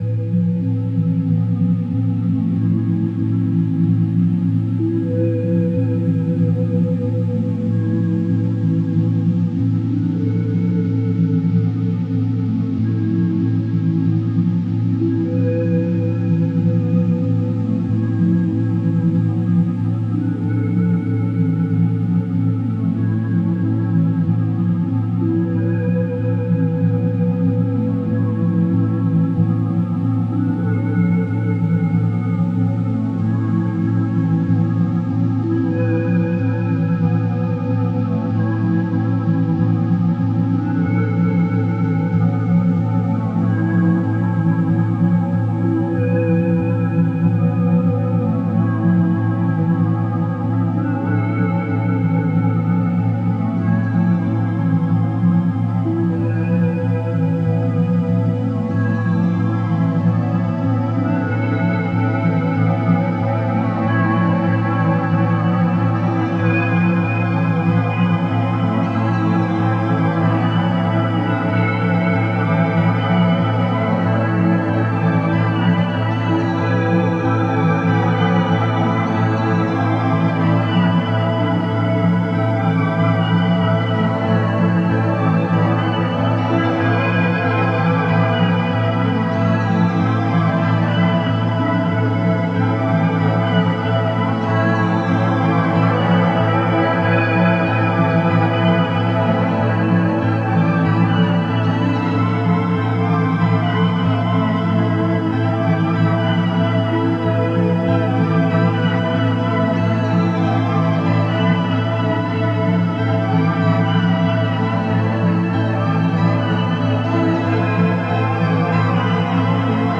Synth Stuff Yamaha PSS480 Music Station
Here are some demos/improvs with some Chinese cheap MOoeR shimverb pedal and chorus pedal connected to the PSS480 through a Signature edition Soundcraft mixer…the PSS480 really sparkles with some chorus effect over it:
Moody Rainy Spy Soundtracks
cloudy Cold War FM pastels
LegoweltYamahaPSS480demo-SpySoundtracks.mp3